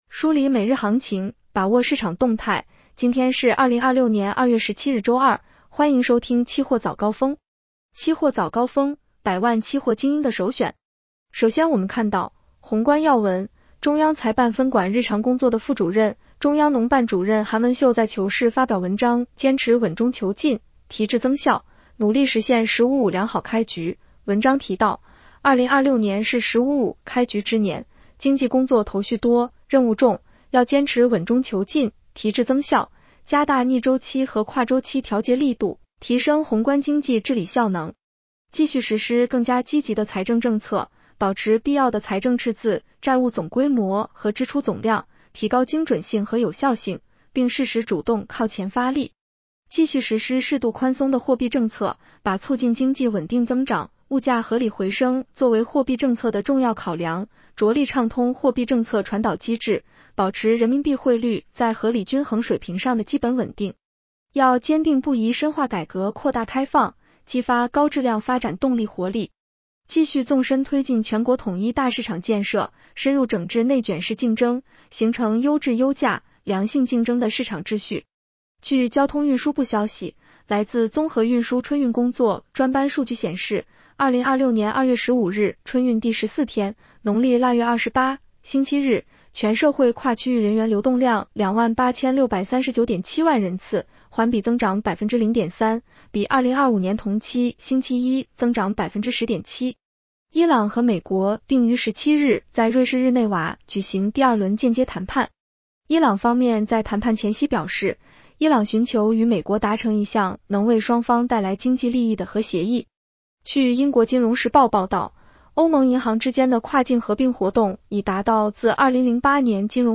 期货早高峰-音频版
期货早高峰-音频版 女声普通话版 下载mp3 热点导读 1.